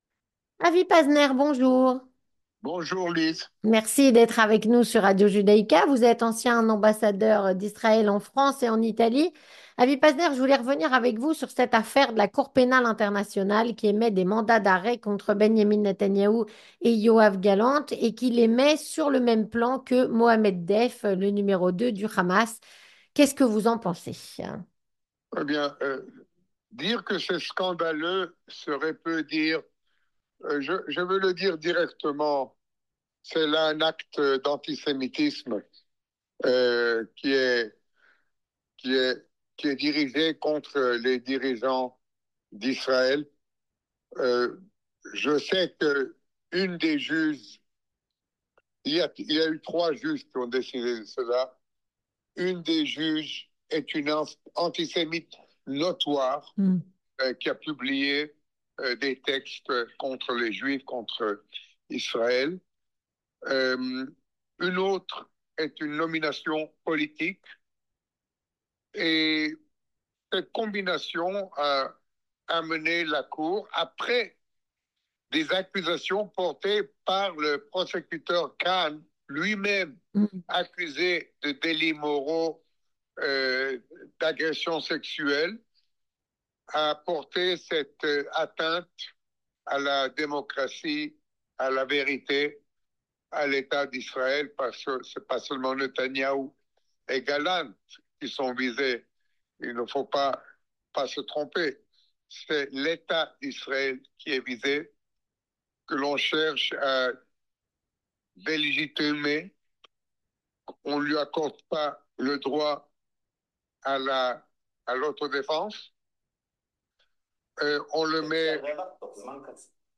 Avec Avi Pazner, ancien ambassadeur d'Israël en France et en Italie